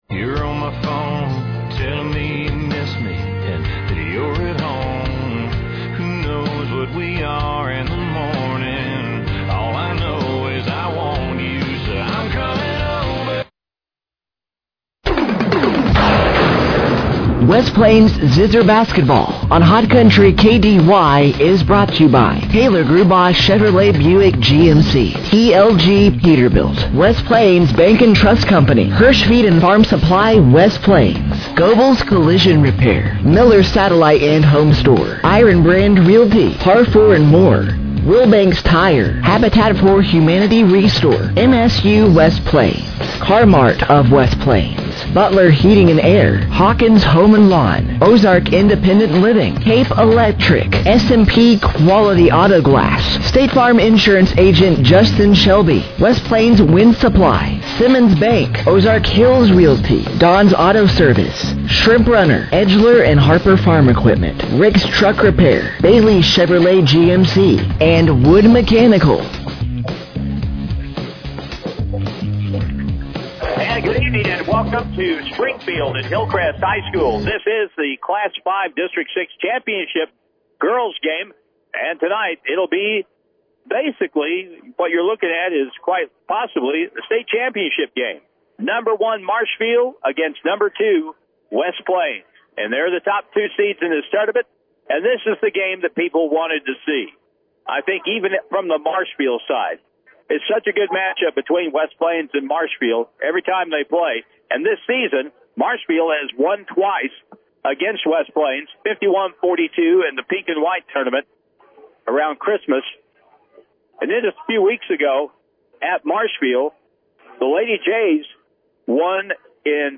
The West Plains Lady Zizzers played in the Championship Game of The Class 5 District 6 Tournament on Friday night, March 7th, 2026 from Springfield-Hillcrest High School, Facing the 25-3- Marshfield Lady Jays